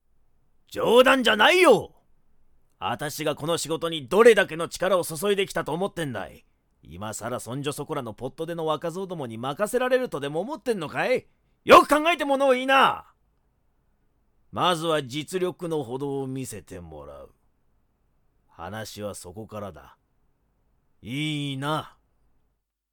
⑦ 老け
老け.mp3